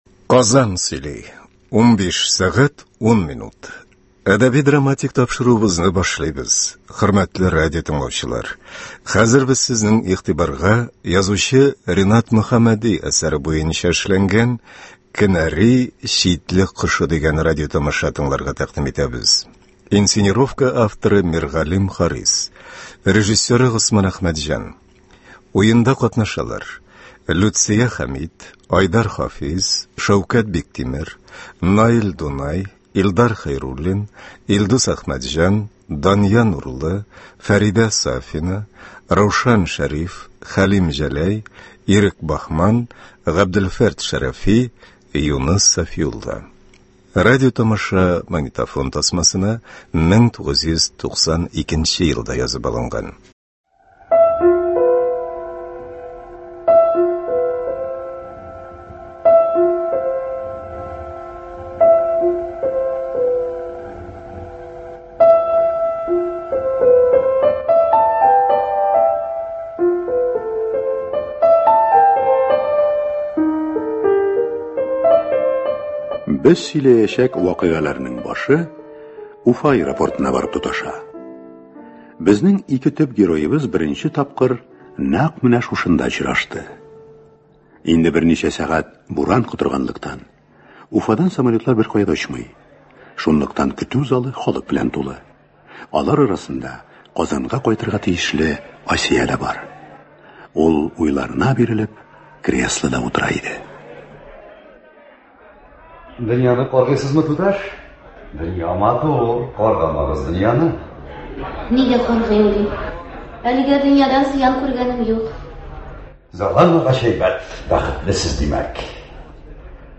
Радиоспектакль (06,07.02.21) | Вести Татарстан
Ул магнитофон тасмасына 1992 елда язып алынган. Уенда Г.Камал исемендәге Татар Дәүләт академия театры артистлары катнаша.